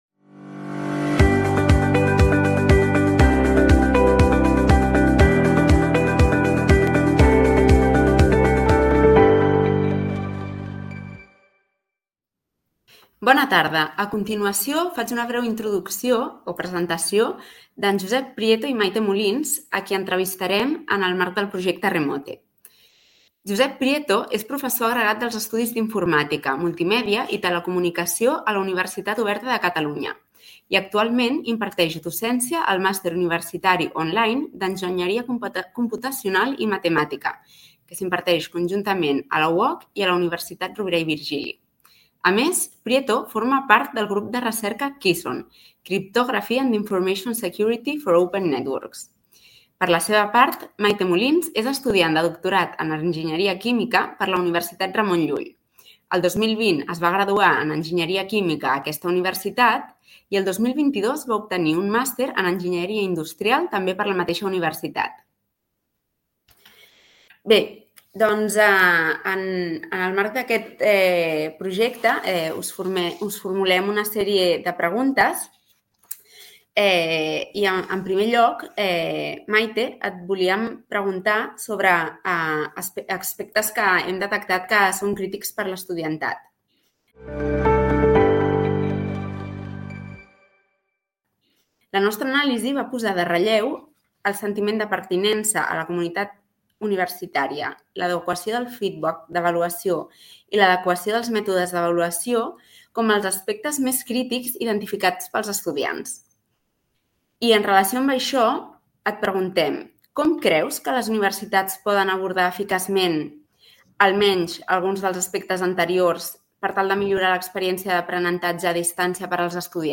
In-Depth Interviews